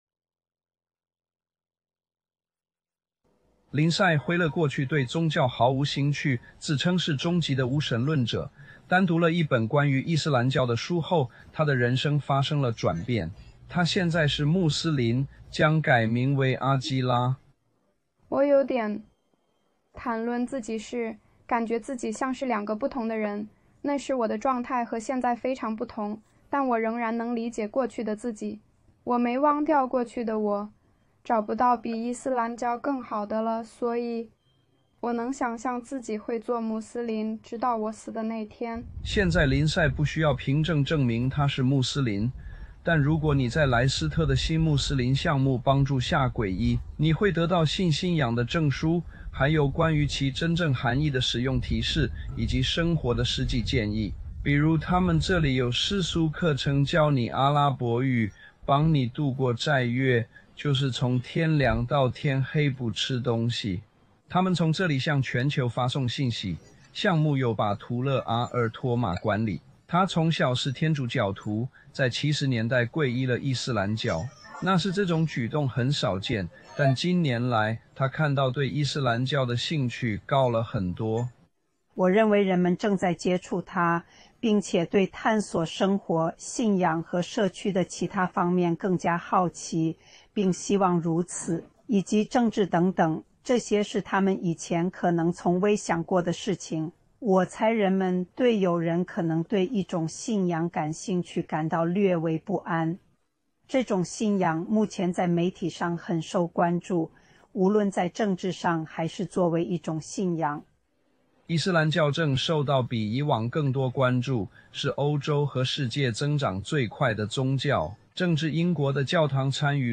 属性: 在这段 Five News 的新闻报道中，记者解释了伊斯兰如何成为英国乃至整个欧洲增长最快的宗教。